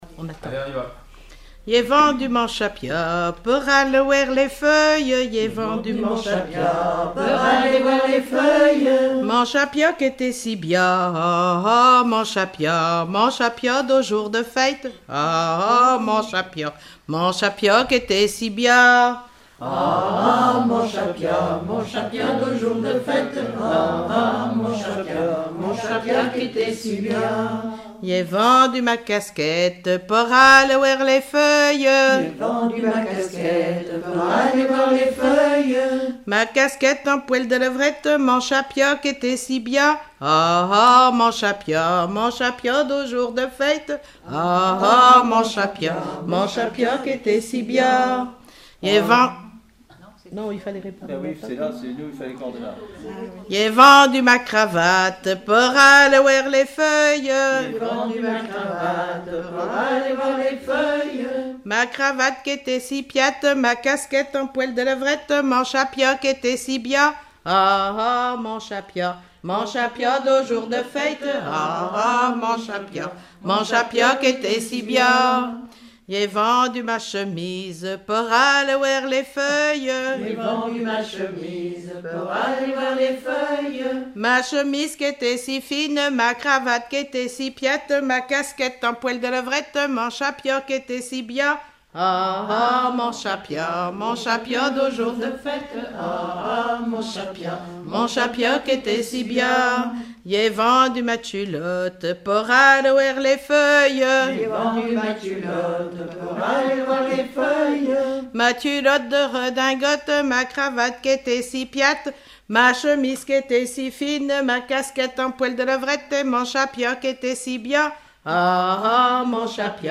Genre énumérative
Collectif-veillée (2ème prise de son)
Pièce musicale inédite